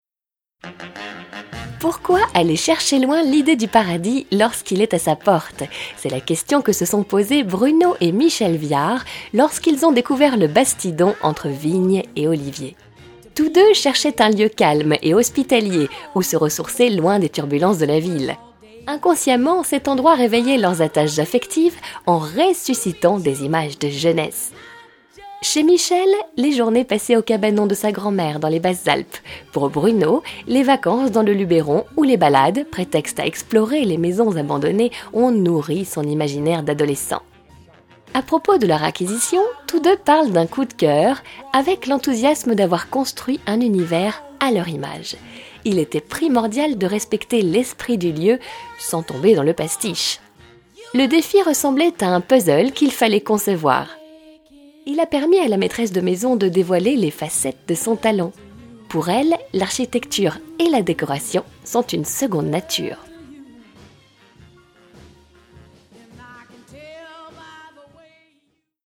Démos voix off
Documentaire Décoration